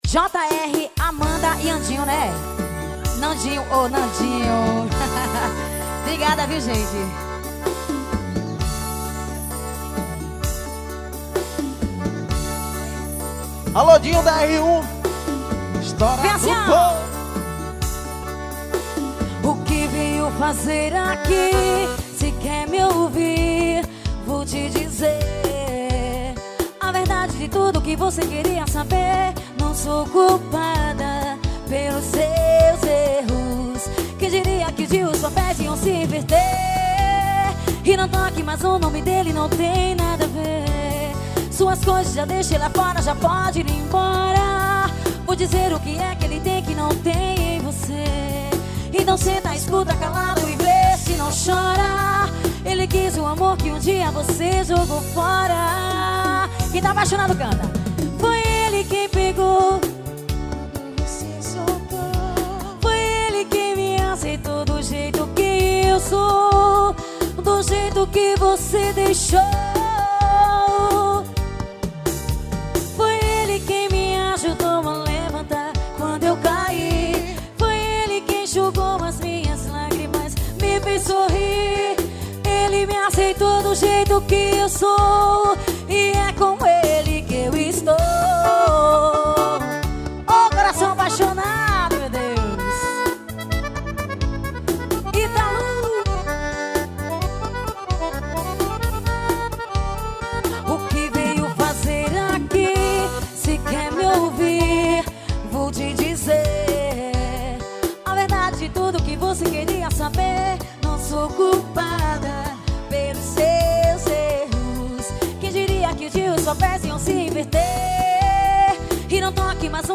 Composição: Ao Vivo.